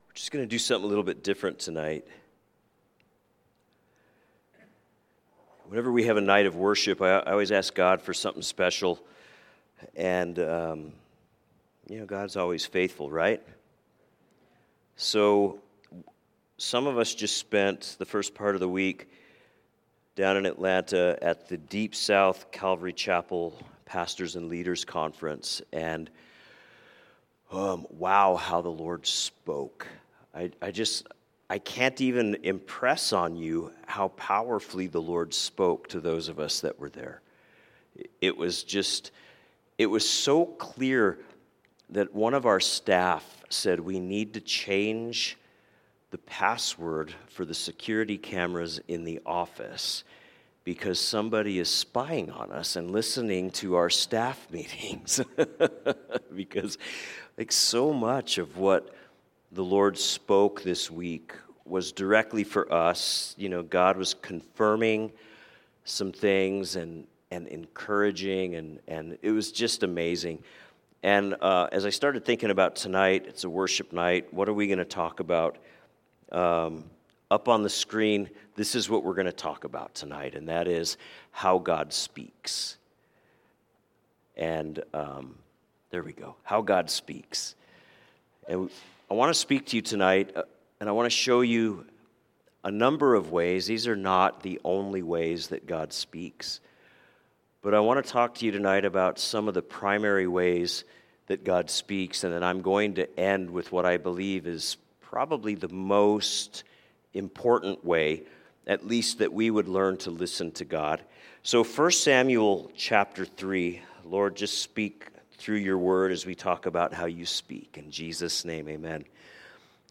A message from the series "Wednesday Evening."